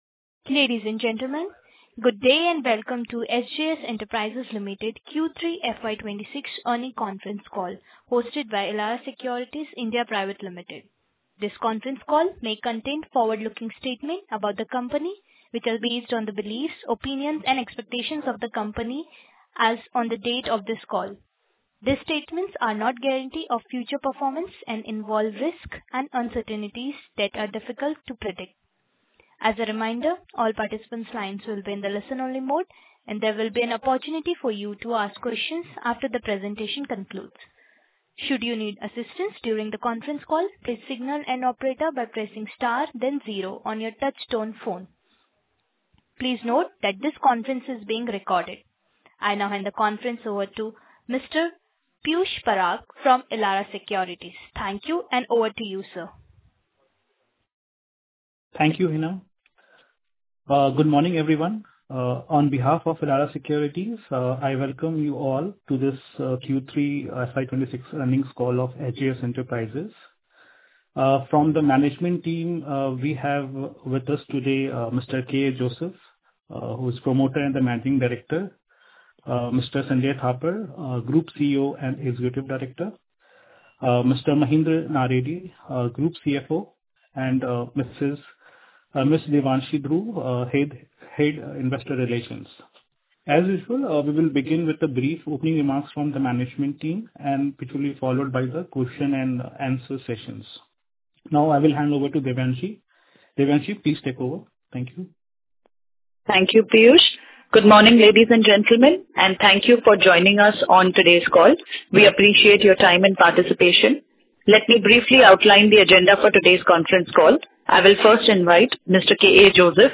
Q3FY26 Earnings Call_Audio.mp3